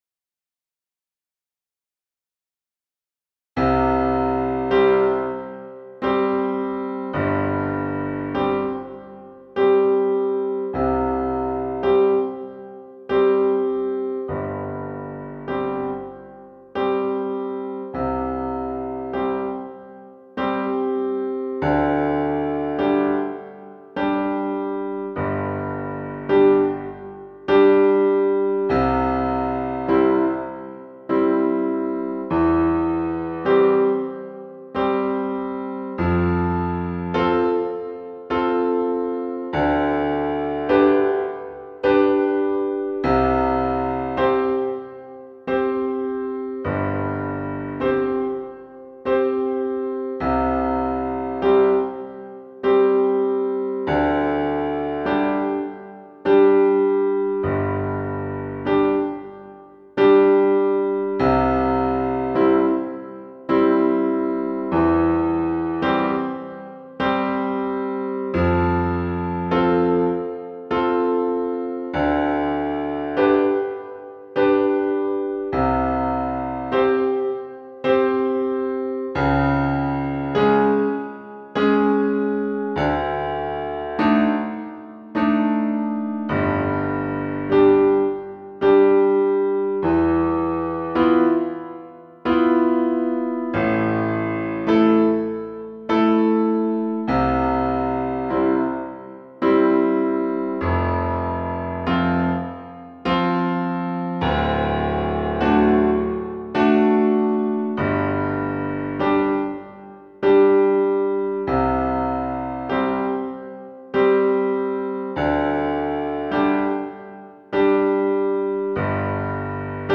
Melodiestimme in langsamem Übungs-Tempo